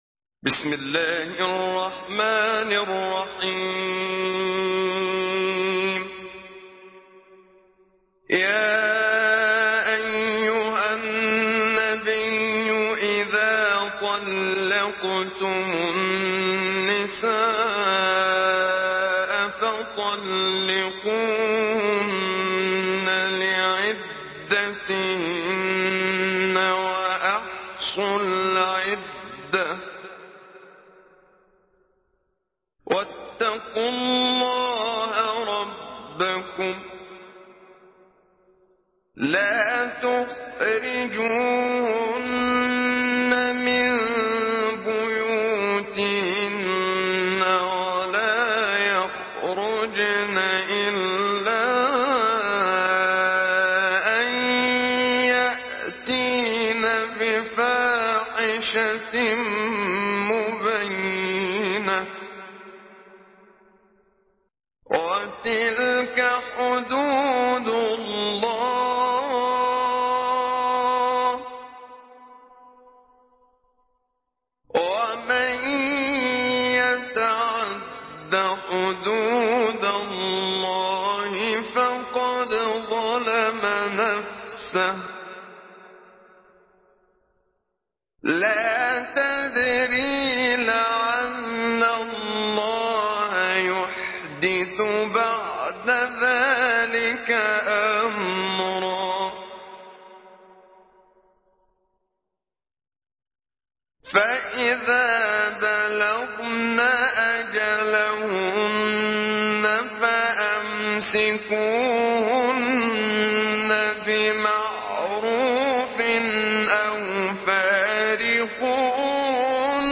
Surah আত-ত্বালাক MP3 in the Voice of Muhammad Siddiq Minshawi Mujawwad in Hafs Narration
Surah আত-ত্বালাক MP3 by Muhammad Siddiq Minshawi Mujawwad in Hafs An Asim narration. Listen and download the full recitation in MP3 format via direct and fast links in multiple qualities to your mobile phone.